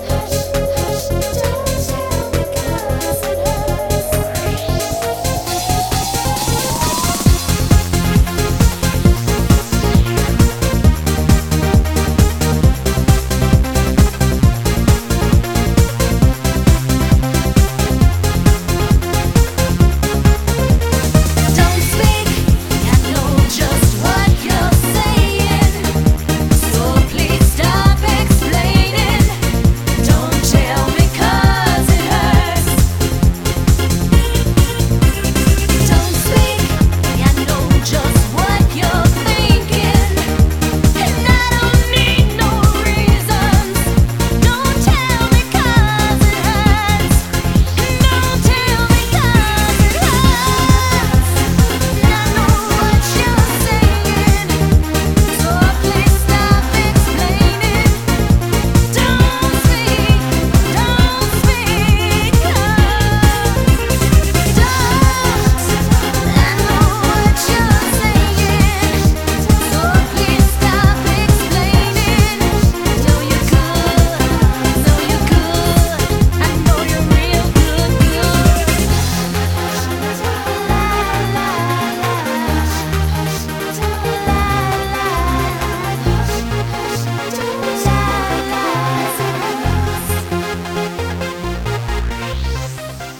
BPM134--1
Audio QualityMusic Cut